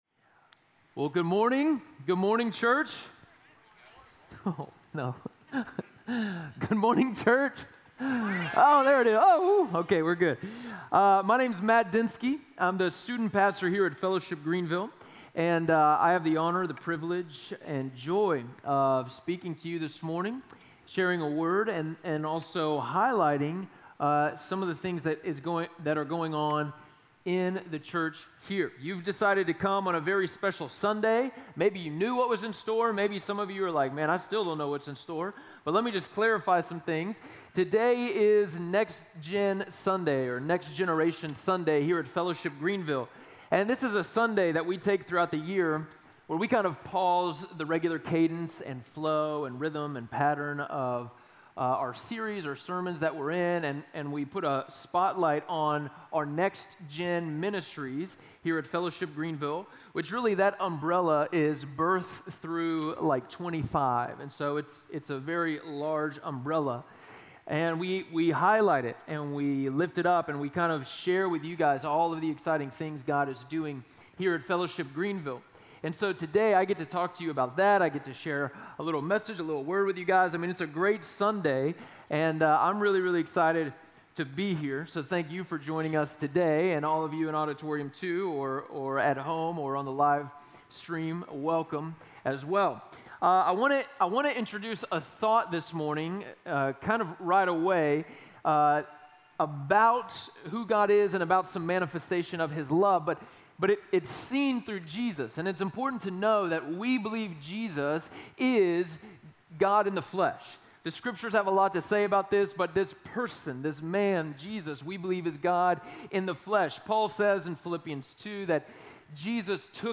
Audio Sermon Notes (PDF) Ask a Question Luke 15 begins with Jesus, who we believe is God manifest with flesh and bone, surrounding himself with what society would call “bad people”.